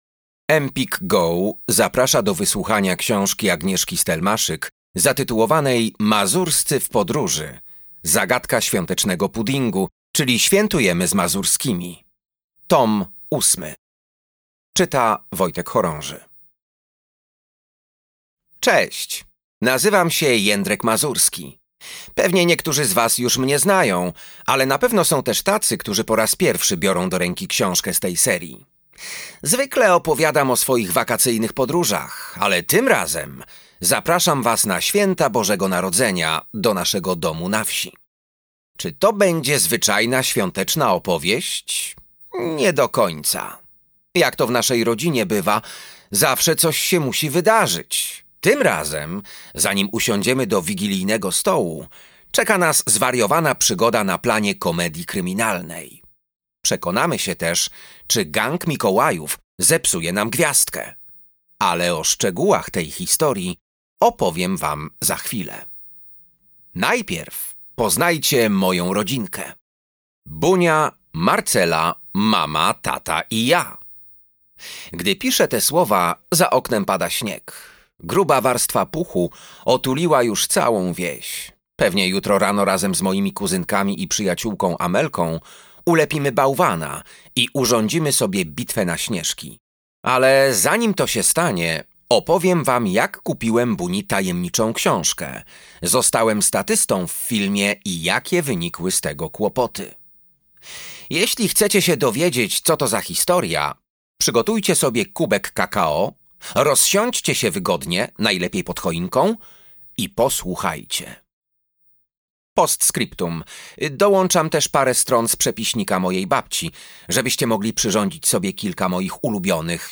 Bracia Świętego Mikołaja - Leszek Talko - audiobook + książka